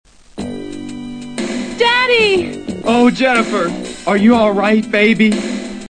And here's some of the wonderful voice acting.